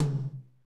Index of /90_sSampleCDs/Northstar - Drumscapes Roland/DRM_R&B Groove/TOM_R&B Toms x
TOM R B H0AL.wav